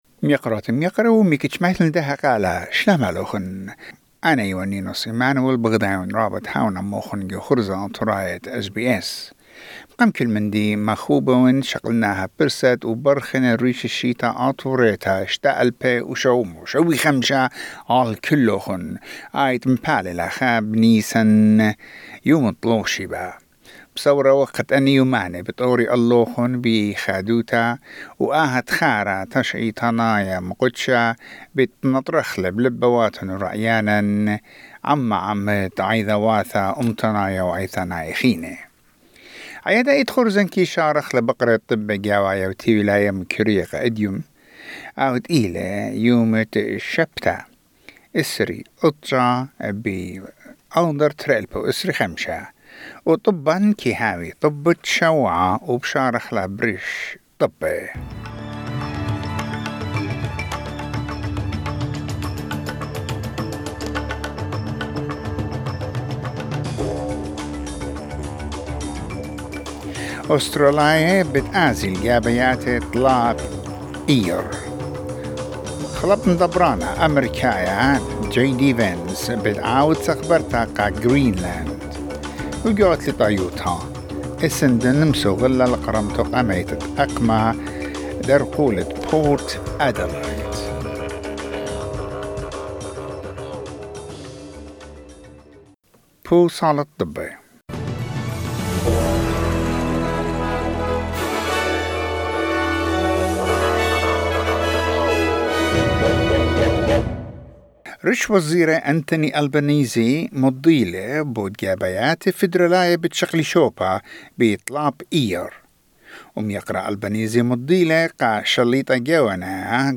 SBS Assyrian: Weekly news wrap